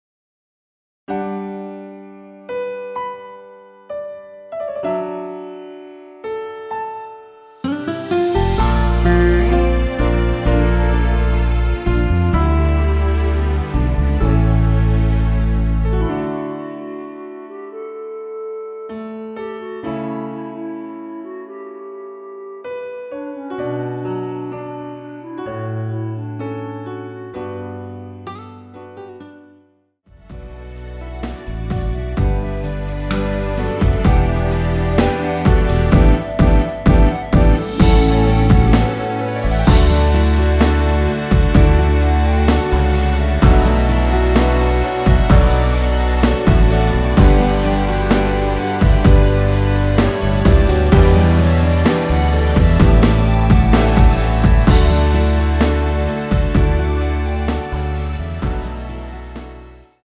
원키 멜로디 포함된 MR입니다.
D
앞부분30초, 뒷부분30초씩 편집해서 올려 드리고 있습니다.
중간에 음이 끈어지고 다시 나오는 이유는